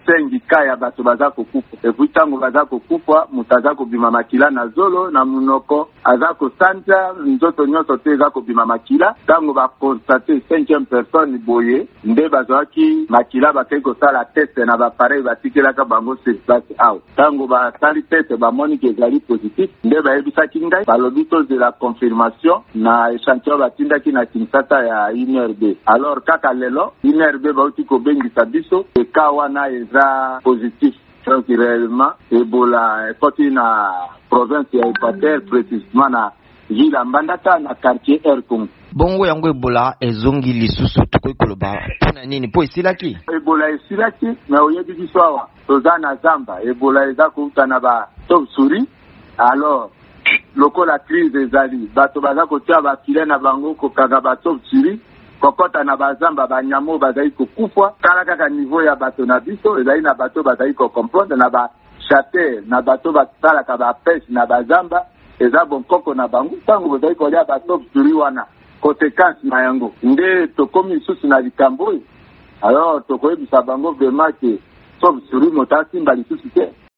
VOA Lingala epesaki malabi na mokambi ya etuka ya Equateur Bobo Boloko Bolumbu.